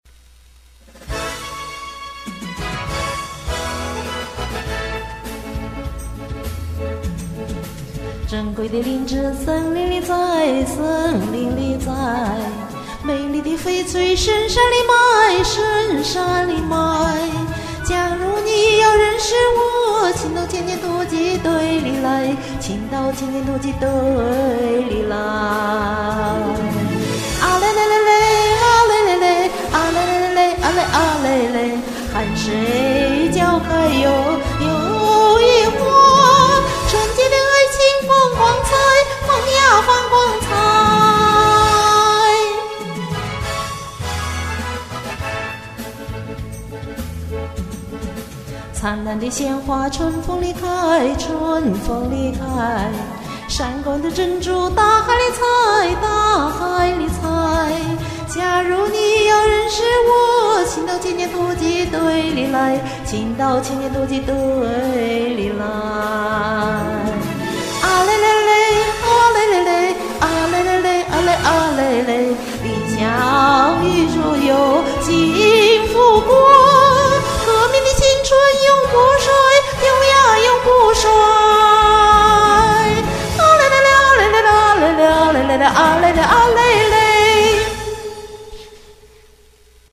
我练过的中音歌
这首歌节奏很快
这歌真欢快，舒畅悦耳！